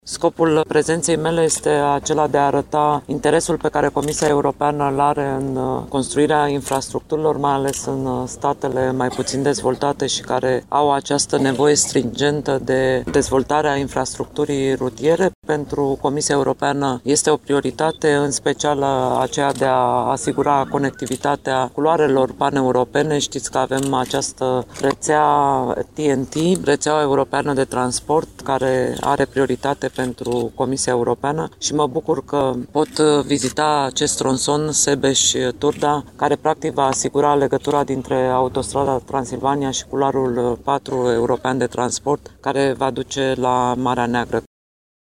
Vizita a avut loc la km 67, în zona localităţii Bădeni, unde Corina Creţu a declarat: